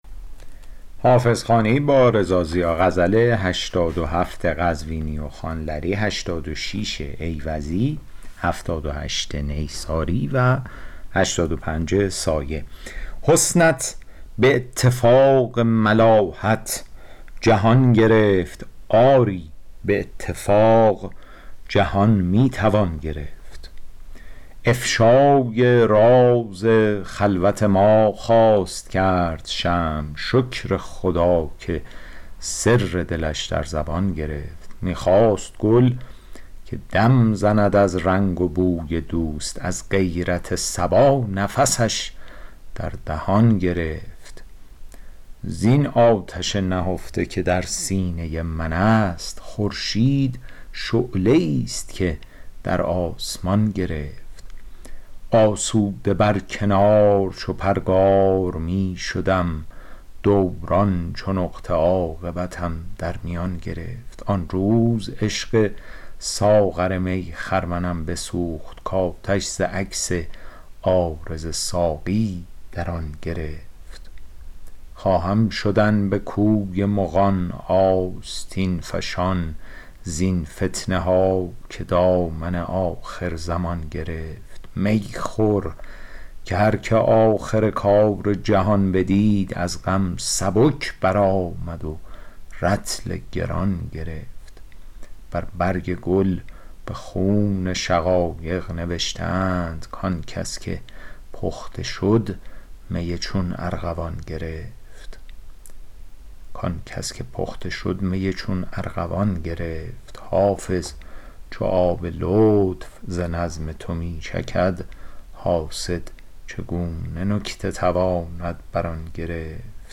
شرح صوتی